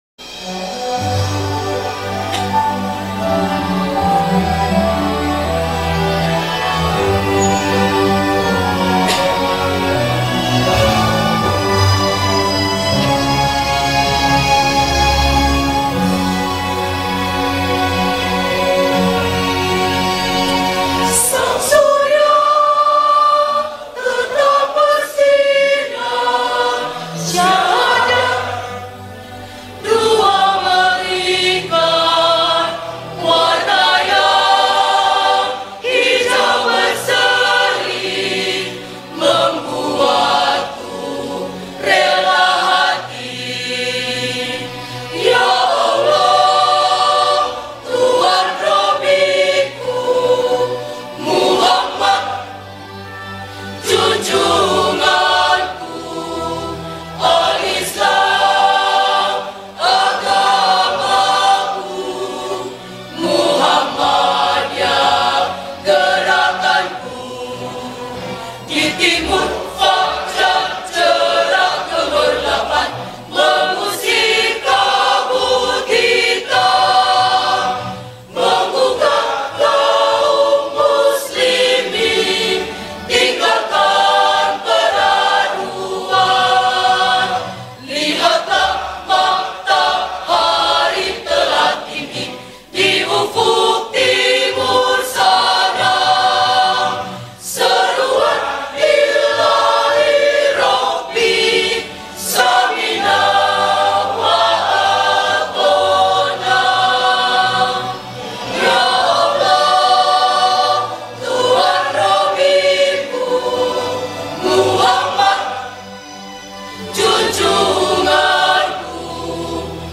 MOMEN PADUAN SUARA MAHASISWA UNIVERSITAS sound effects free download
PADUAN SUARA
MENYANYIKAN LAGU SANG SURYA